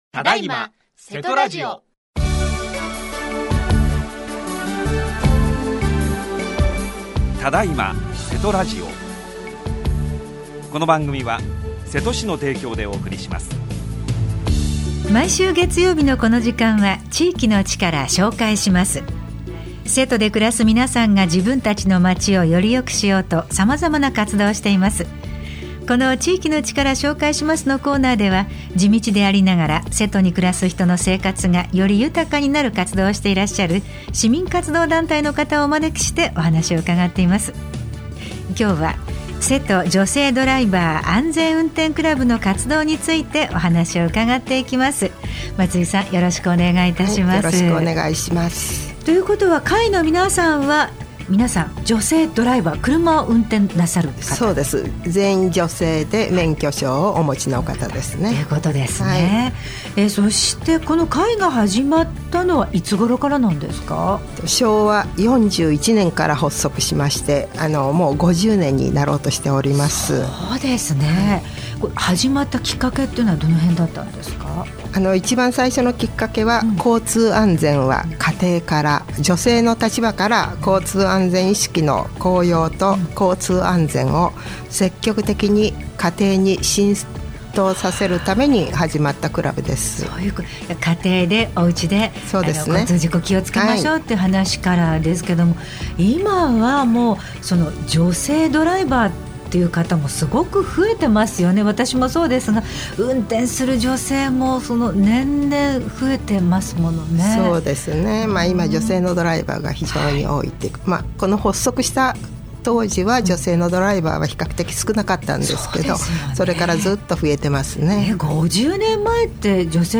27年12月28日（月） 毎週月曜日のこの時間は、〝地域の力 紹介します〝 このコーナーでは、地道でありながら、 瀬戸に暮らす人の生活がより豊かになる活動をしていらっしゃる 市民活動団体の方をお招きしてお話を伺います。